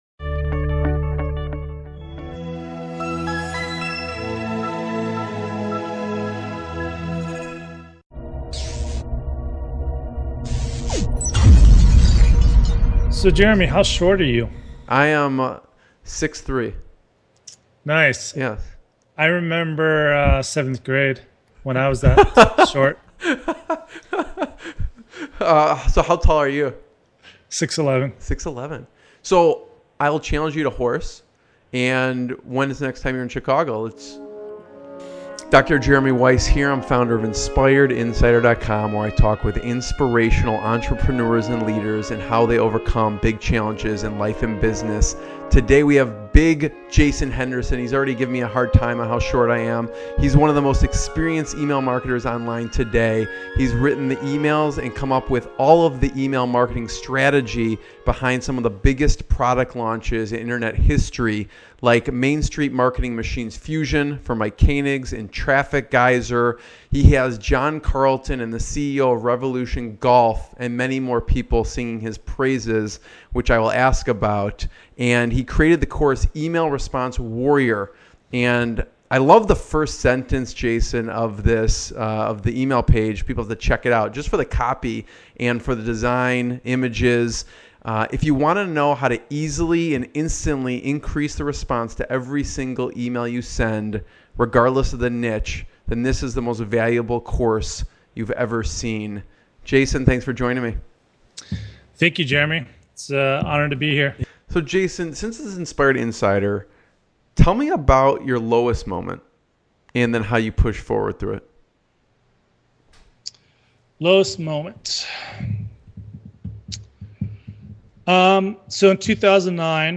INspired INsider - Inspirational Business Interviews with Successful Entrepreneurs and Founders